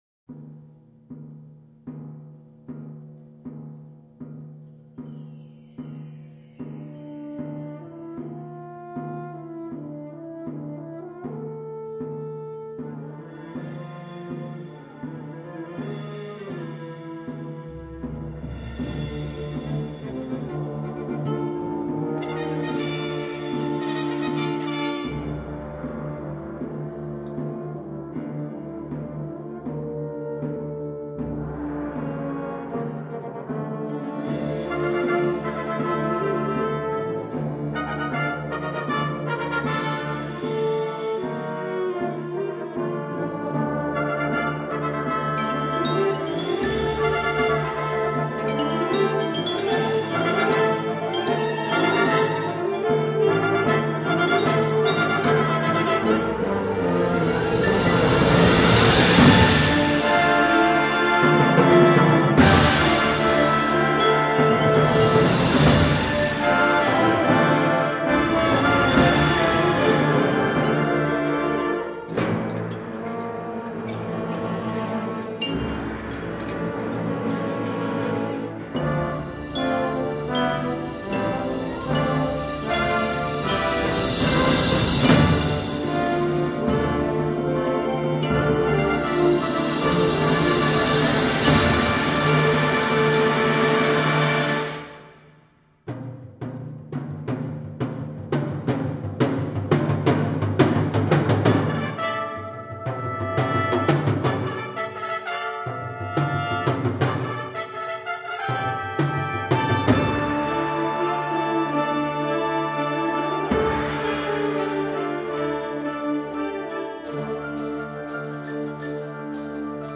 Genre: Band
Percussion 2 (suspended cymbal, Chinese cymbal, hi-hat)